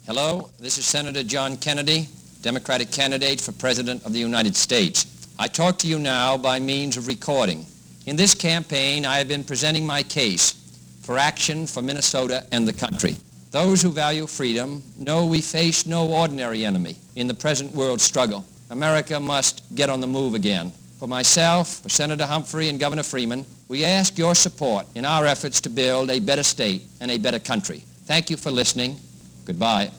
Location Internet John F. Kennedy (JFK) campaign spot, approximately 1959. 1 digital audio file (30 seconds): MP3 (634 KB).
JFK Radio Spot_MN_30-sec.mp3